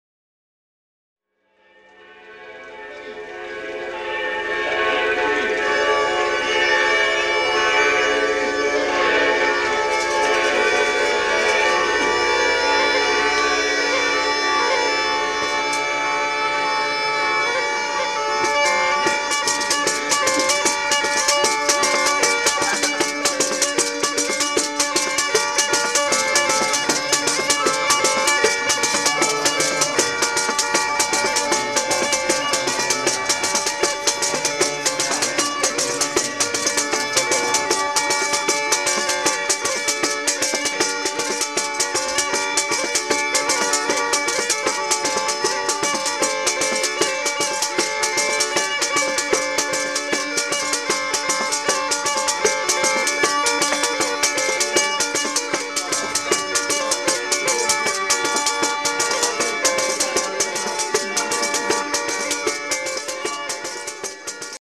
Жанр: Mafia Folk